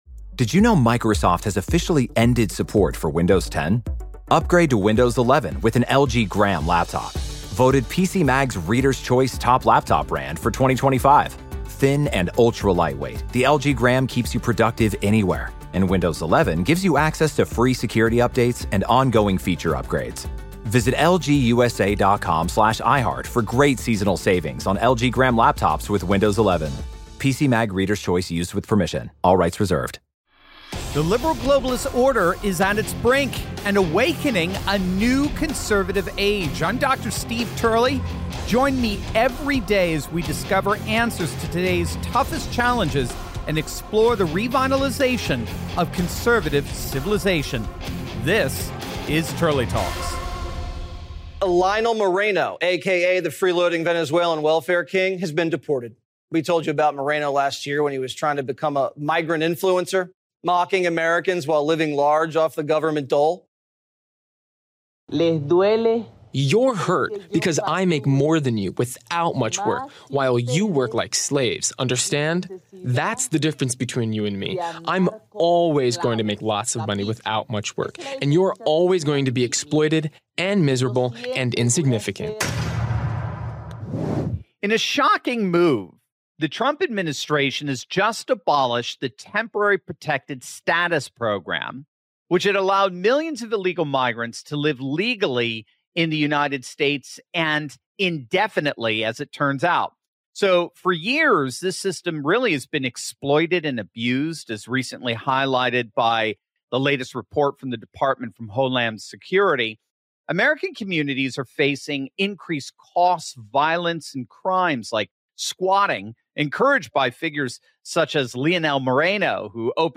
From squatting scams to overwhelmed schools, citizens are feeling the pressure. Expert guest